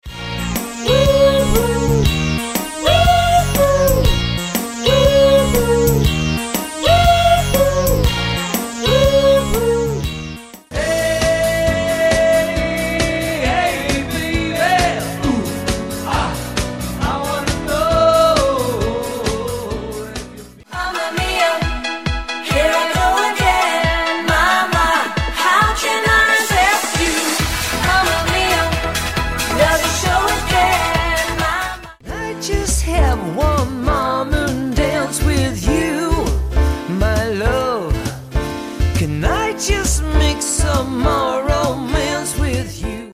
Male/Female vocal duo